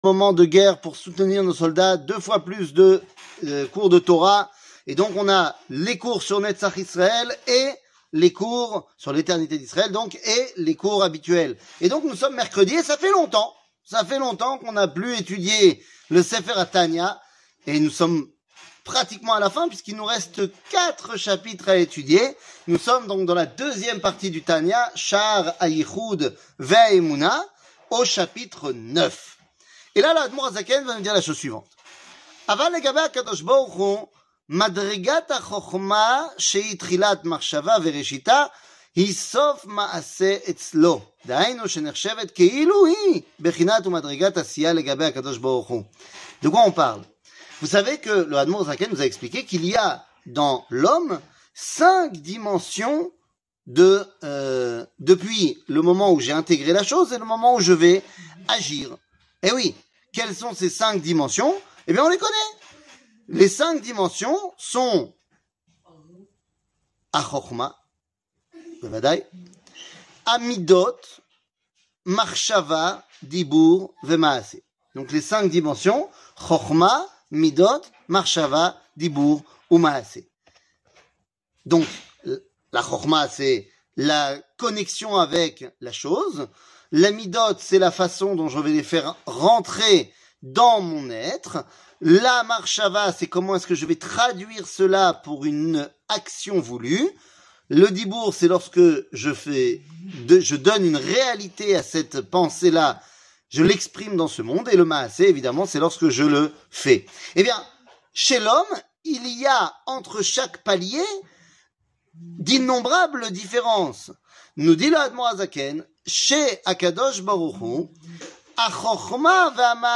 Tania, 63, Chaar Ayihoud Veaemouna, 9 00:06:25 Tania, 63, Chaar Ayihoud Veaemouna, 9 שיעור מ 11 אוקטובר 2023 06MIN הורדה בקובץ אודיו MP3 (5.86 Mo) הורדה בקובץ וידאו MP4 (10.85 Mo) TAGS : שיעורים קצרים